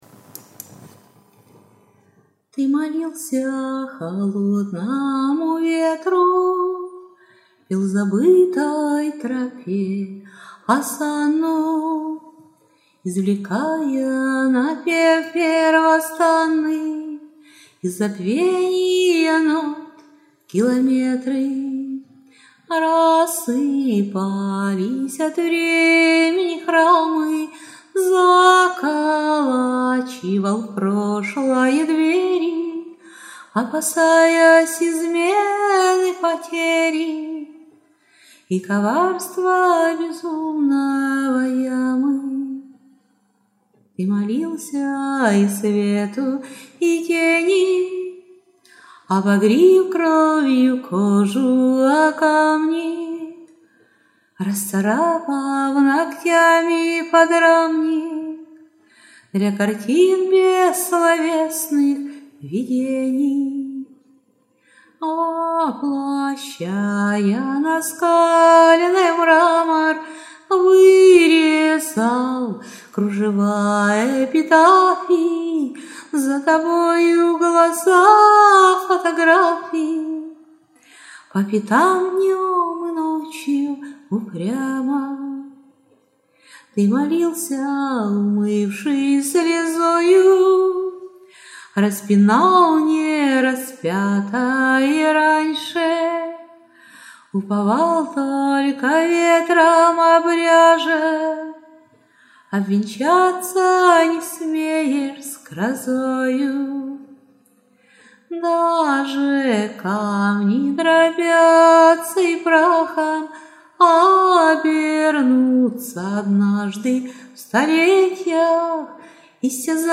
Представляю озвученный вариант стихотворения,представленного ранее.
Неймовірно!!!Красиво, мелодійно,гарно тонічно...Браво!!! hi 39 flo18 flo36